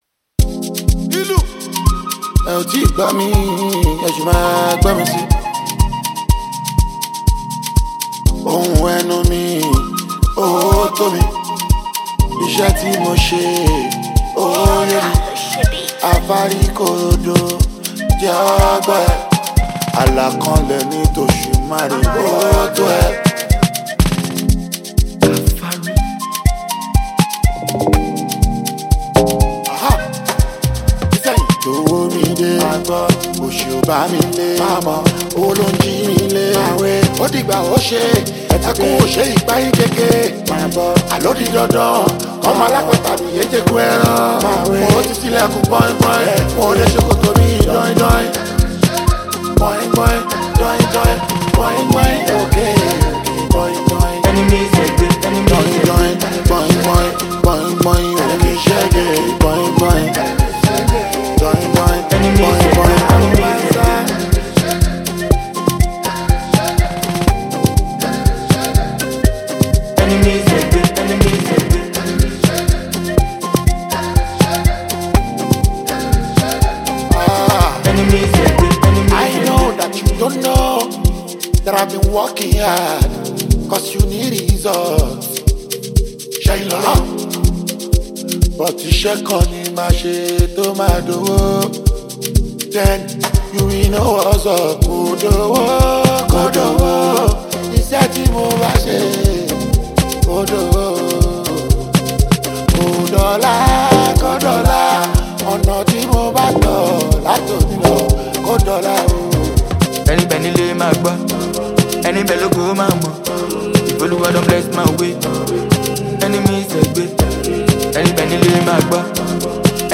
mesmerizing beat